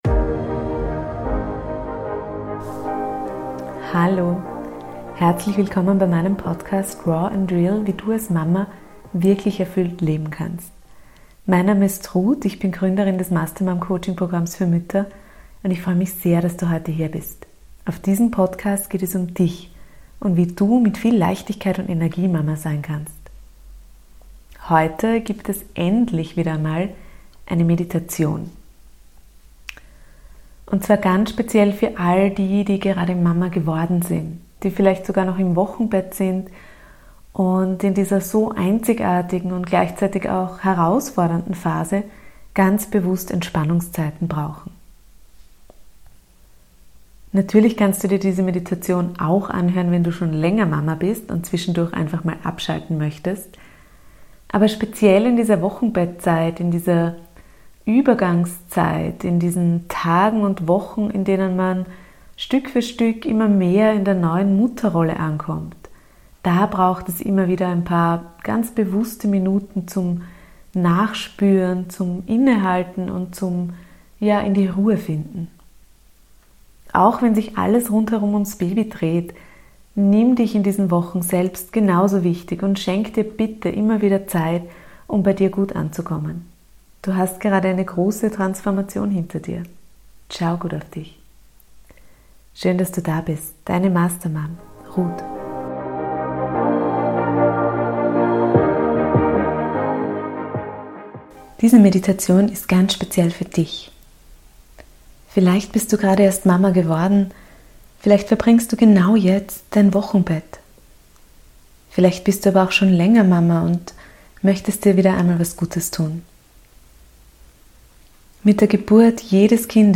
Heute gibt es wieder einmal eine Meditation. Und zwar ganz speziell für alle, die gerade Mama geworden sind, die vielleicht sogar noch im Wochenbett sind und in dieser so einzigartigen und gleichzeitig so herausfordernden Phase ganz bewusste Entspannungszeiten benötigen.